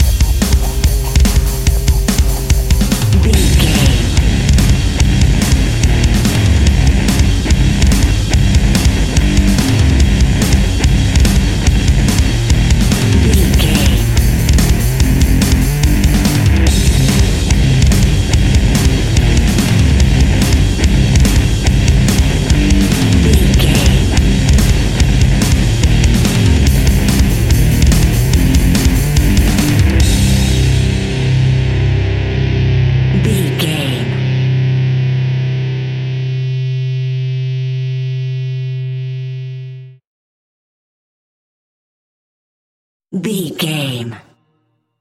royalty free music
Epic / Action
Fast paced
Aeolian/Minor
hard rock
heavy metal
distortion
Rock Bass
heavy drums
distorted guitars
hammond organ